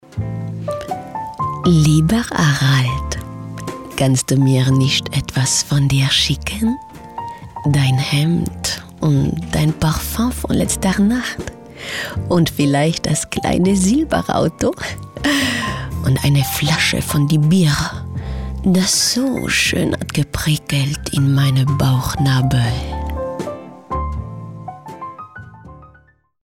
Hörspiel Hochdeutsch (CH)
Schauspielerin mit breitem Einsatzspektrum.